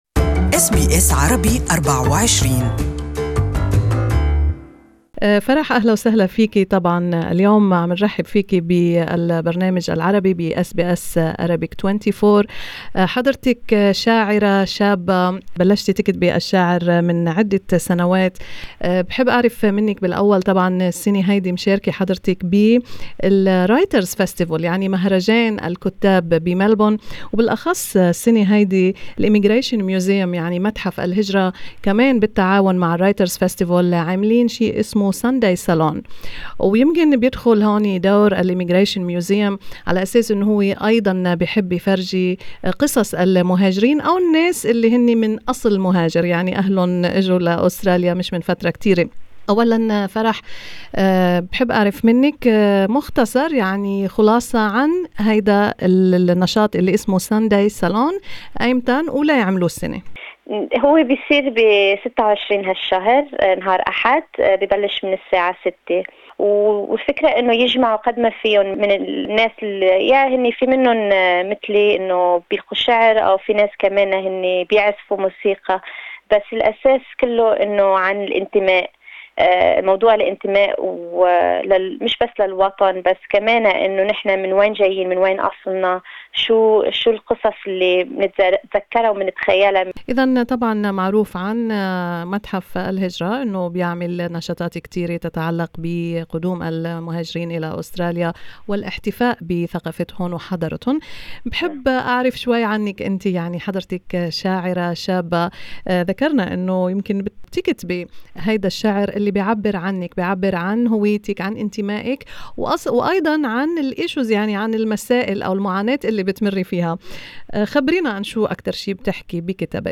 This is an interview in Arabic